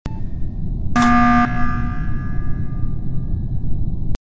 emergency meeting sound among us sound effects
emergency-meeting-sound-among-us